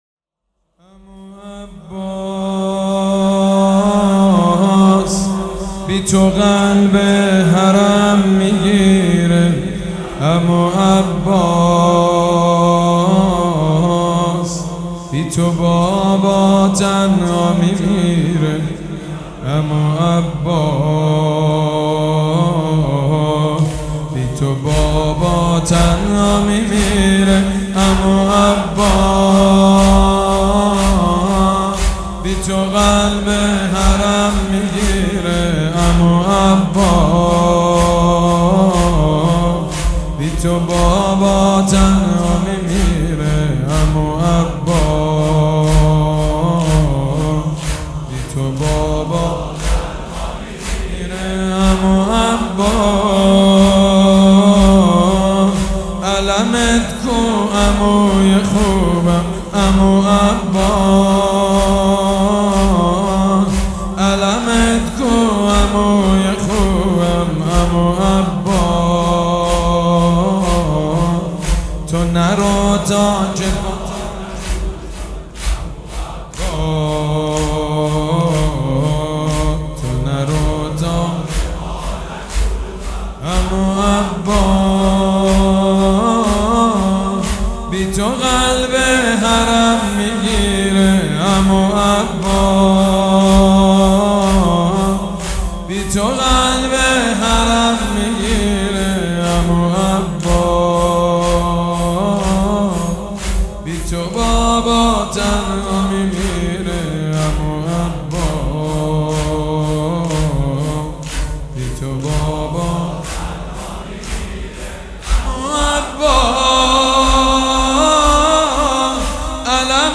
(اين آلبوم گلچيني از مداحي سال هاي قبل است.)